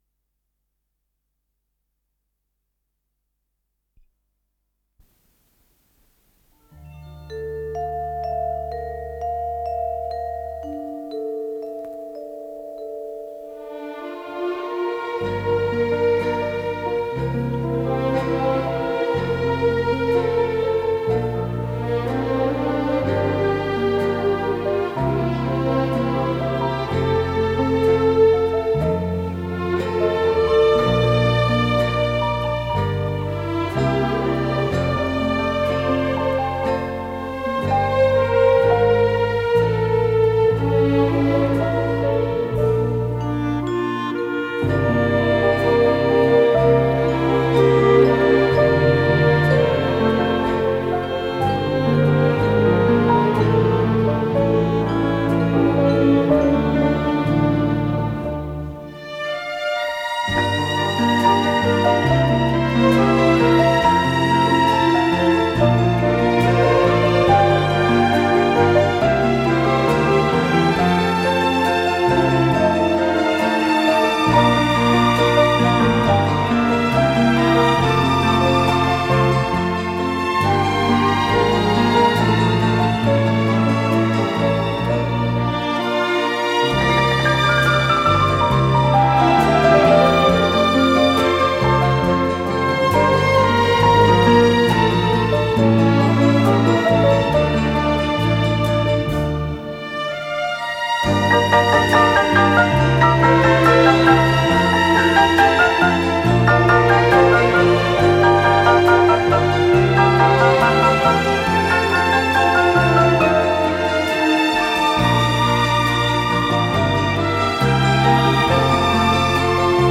с профессиональной магнитной ленты
инструментальный вариант песни, фа мажор
ВариантДубль моно